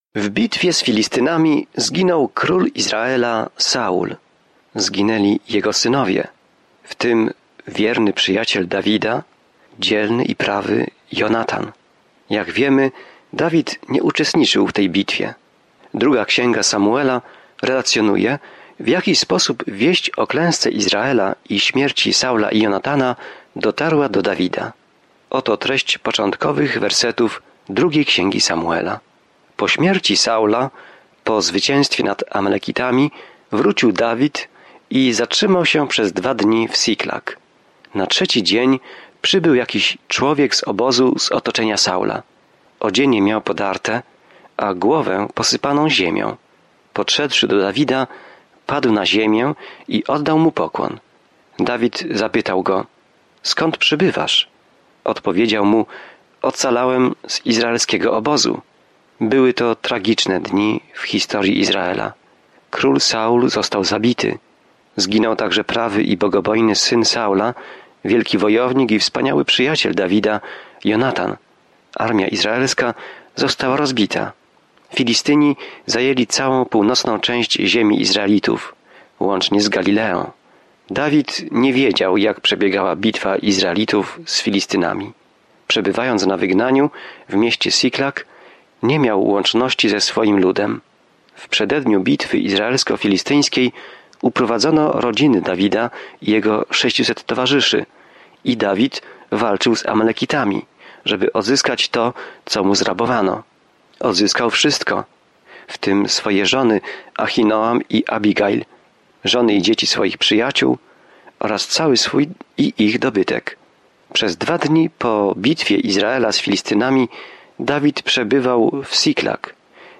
Codziennie podróżuj przez 2 Księgę Samuela, słuchając studium audio i czytając wybrane wersety ze słowa Bożego.